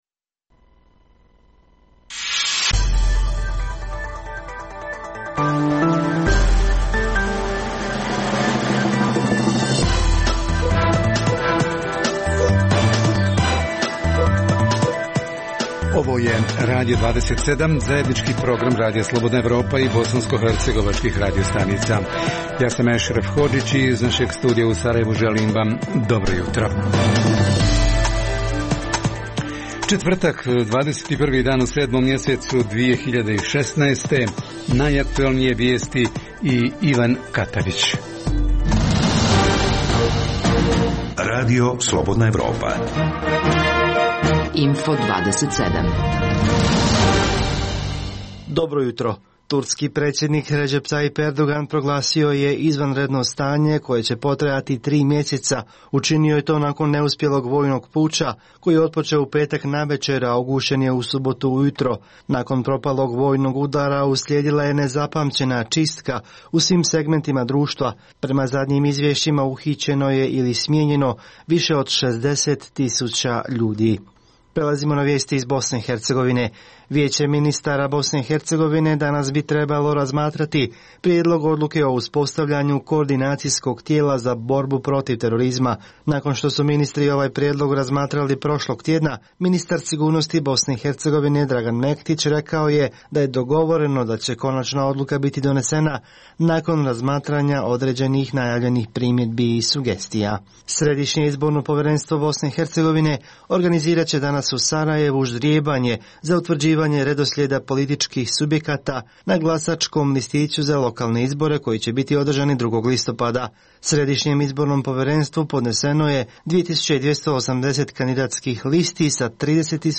Središnji tema jutra je: Romi, kao nacionalna manjina u BiH – kako su organizirani i kako svoja prava ostvaruju u lokalnoj zajednici? Naši reporteri tražili su odgovore u Vitezu, Banjoj Luci i Usori.